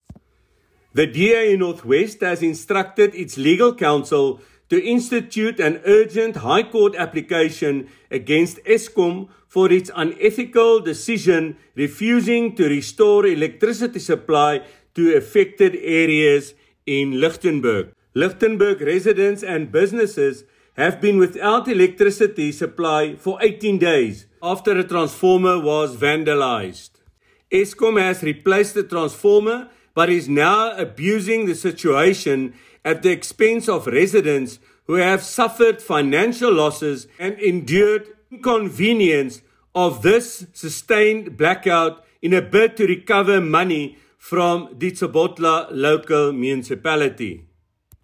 Issued by Leon Basson MP – DA North West Provincial Leader
Note to Broadcasters: Please find linked soundbites in
DA-legal-action-Eskom-Leon-Basson-ENG.mp3